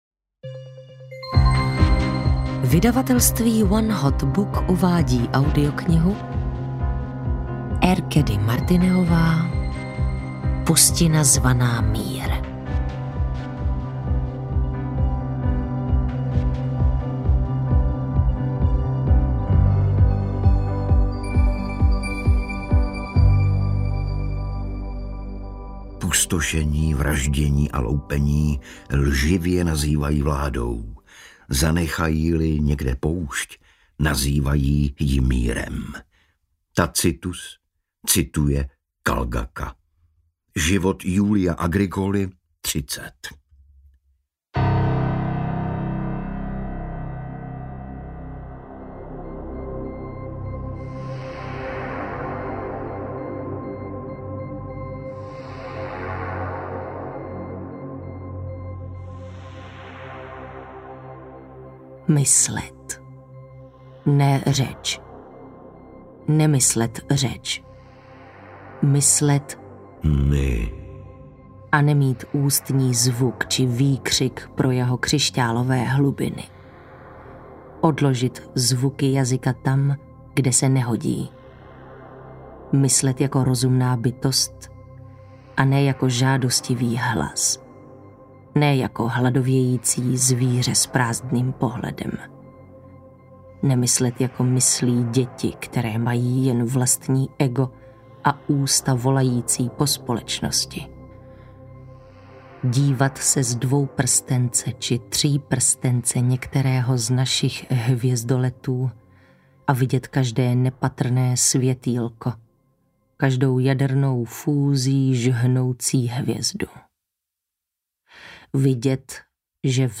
Pustina zvaná mír audiokniha
Ukázka z knihy
• InterpretTereza Dočkalová, Igor Bareš